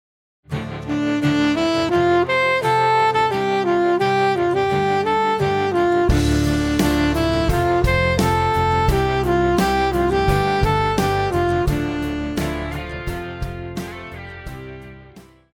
流行
中音萨克斯风
乐团
演奏曲
朋克
仅伴奏
没有主奏
有节拍器